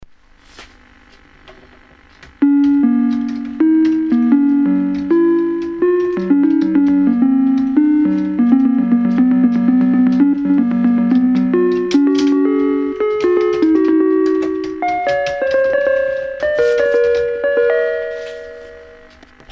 left the recorder in front of the TV so its not HD quality.
Crude recordings of my endeavours today
Me bashing the keyboard [ Dixons Music Player ]